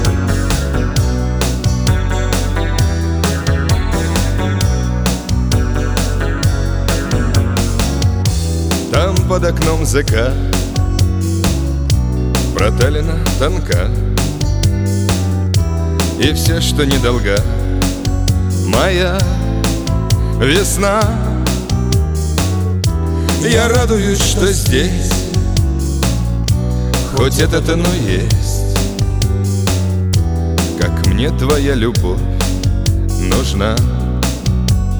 Жанр: Русский поп / Русский рэп / Русский шансон / Русские